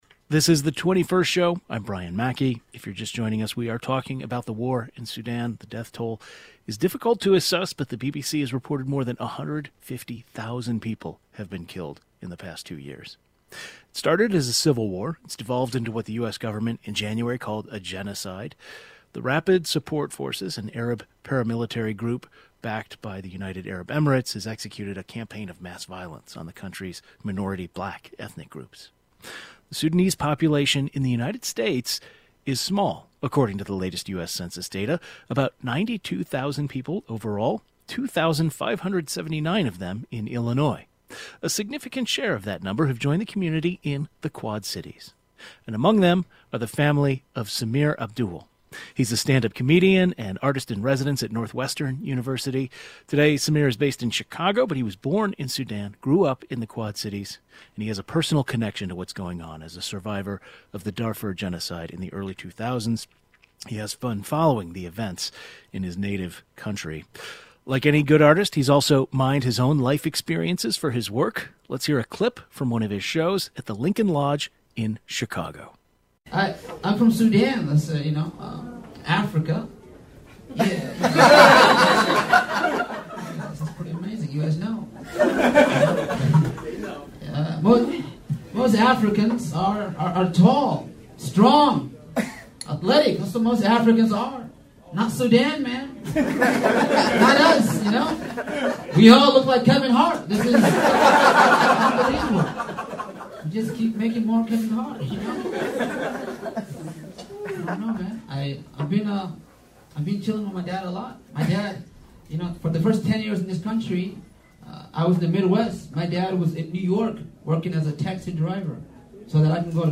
He discusses his work and his personal connection to the current humanitarian crisis in Sudan caused by ongoing war. The 21st Show is Illinois' statewide weekday public radio talk show, connecting Illinois and bringing you the news, culture, and stories that matter to the 21st state.